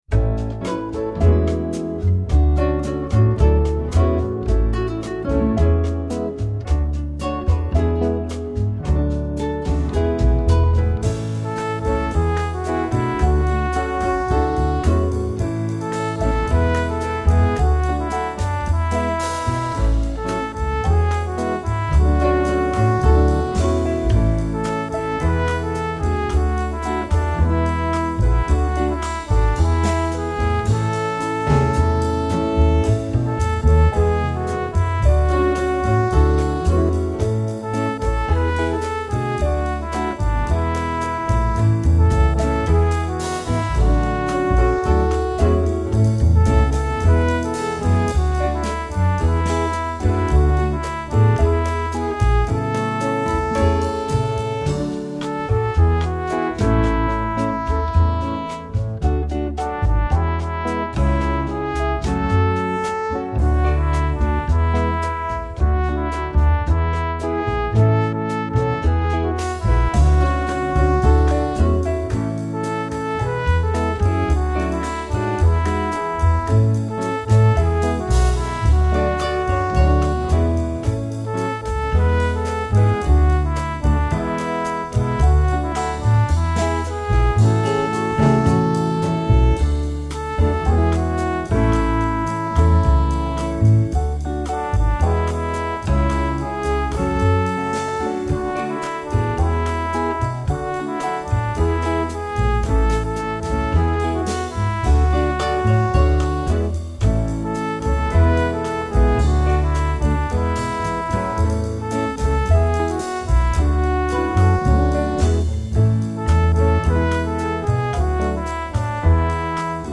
gathering song
with a Latin feel